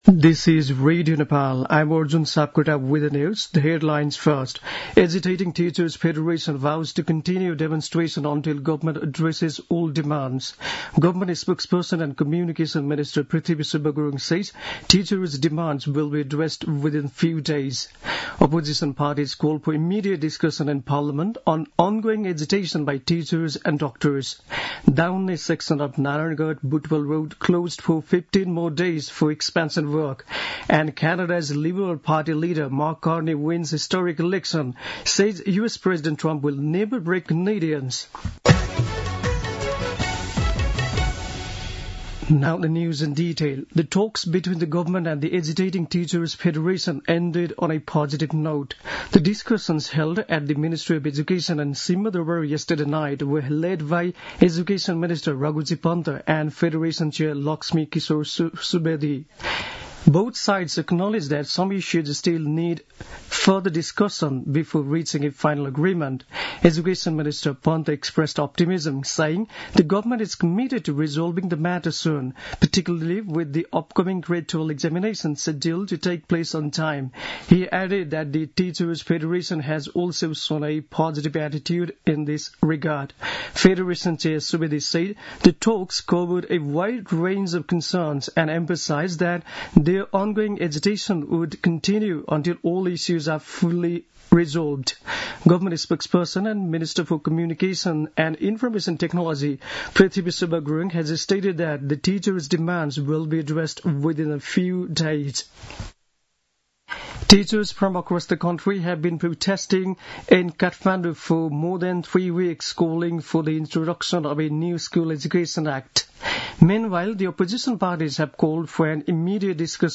दिउँसो २ बजेको अङ्ग्रेजी समाचार : १६ वैशाख , २०८२
2-pm-news-1.mp3